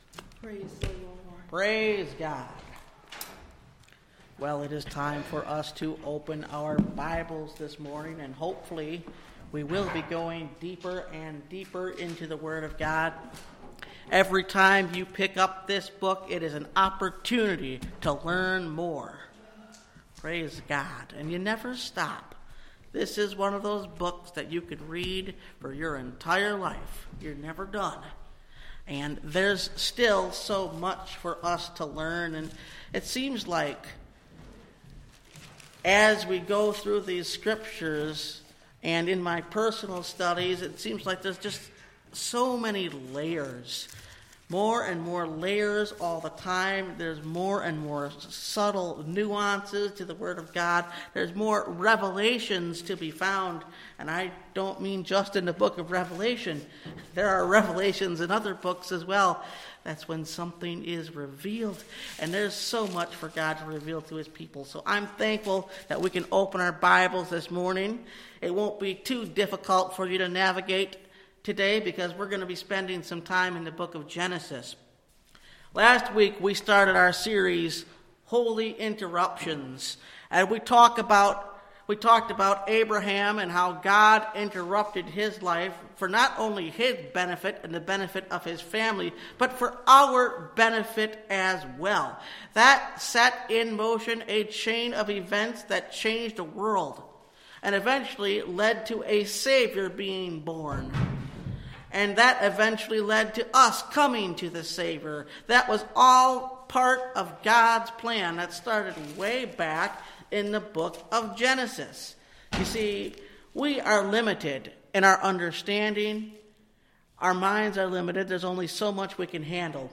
Holy Interruptions – Part 2 (Message Audio) – Last Trumpet Ministries – Truth Tabernacle – Sermon Library